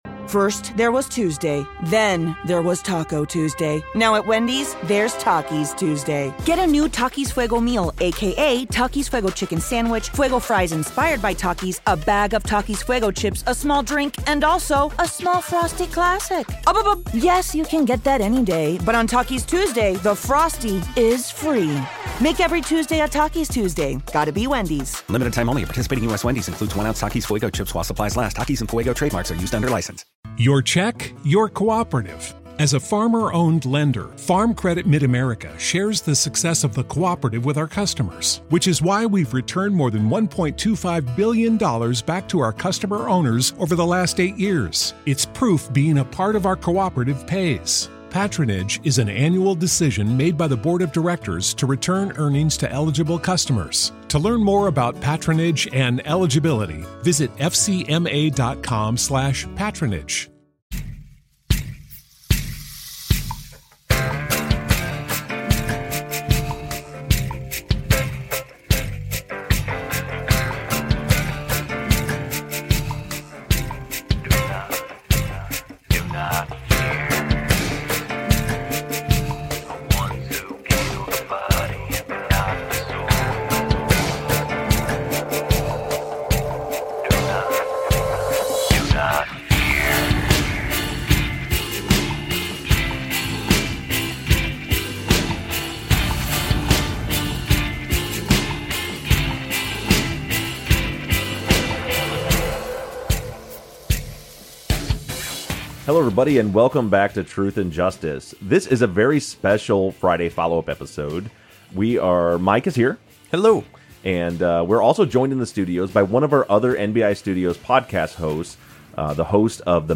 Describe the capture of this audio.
into the studio for a chat.